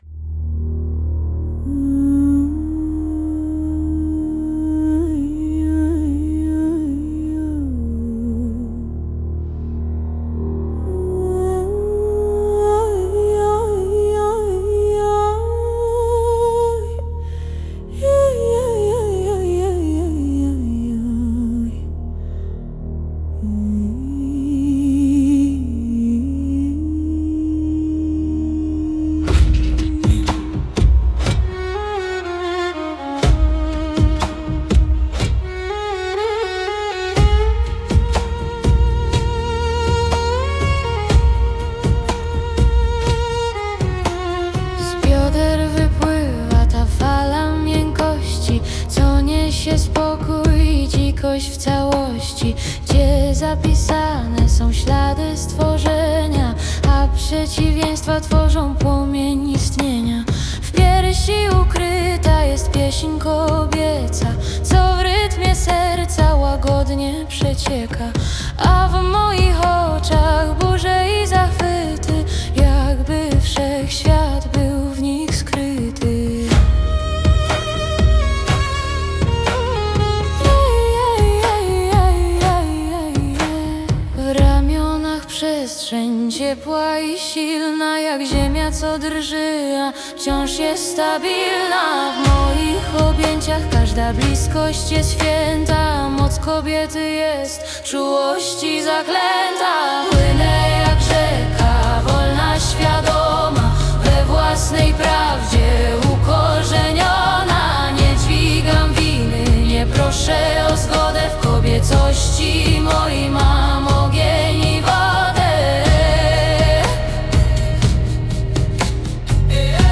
Muzyka wygenerowana z AI